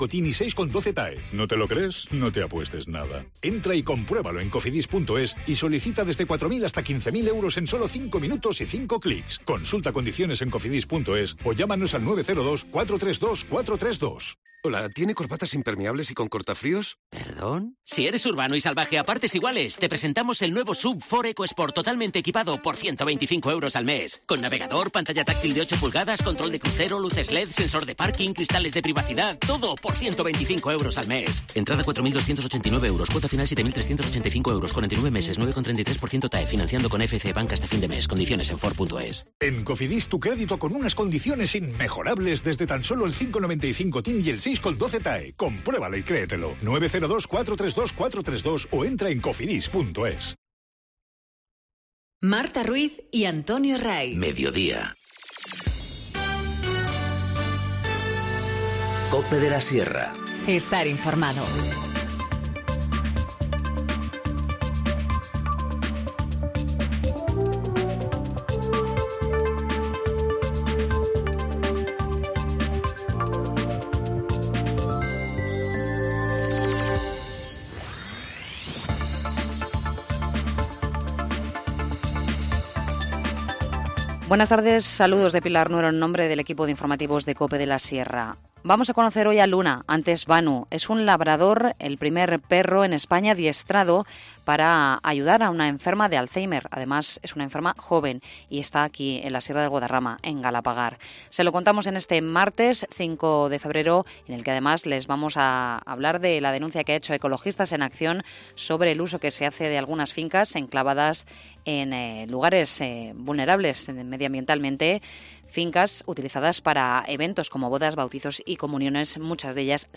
Informativo Mediodía 5 febrero- 14:50h